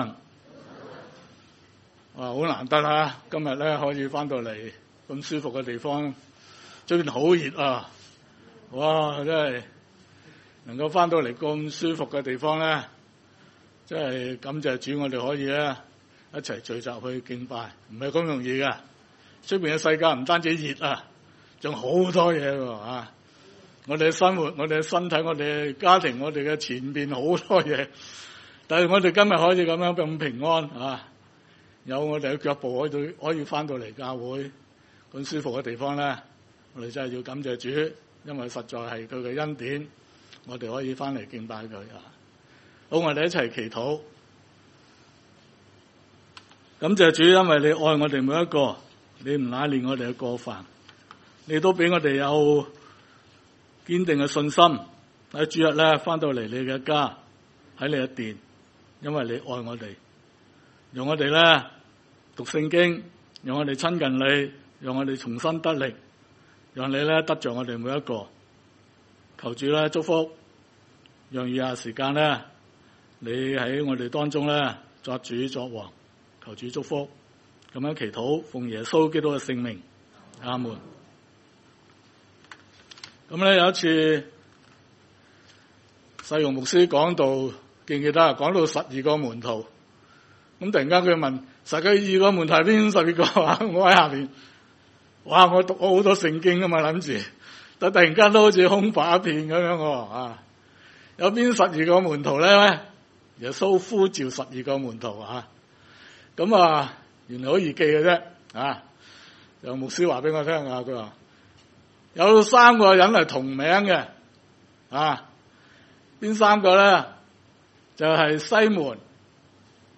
51 崇拜類別: 主日午堂崇拜 36 他見耶穌行走，就說：「看哪，這是神的羔羊！」